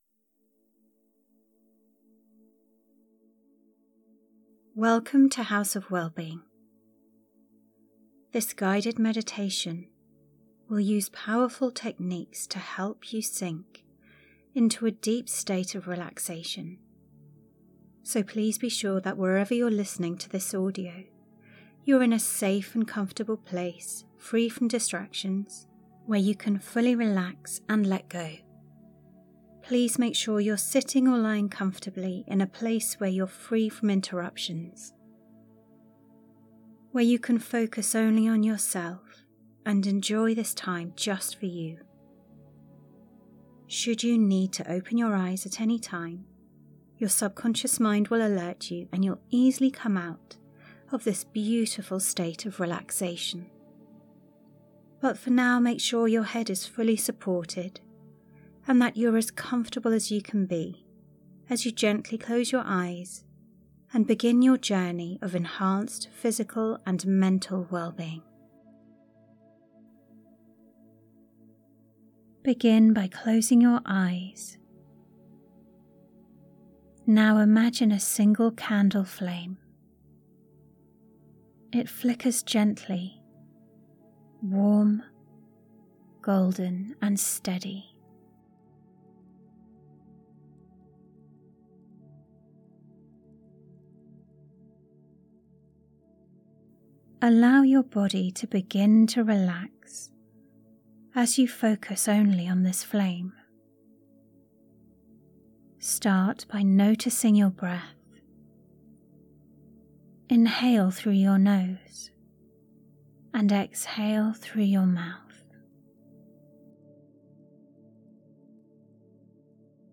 This Releasing Stress Acupressure meditation guides you through calming acupressure points on the feet, wrists, face, and chest to release tension and frustration. Gentle touch, paired with soothing affirmations, helps dissolve stress, quiet the mind, and restore a deep sense of safety and peace.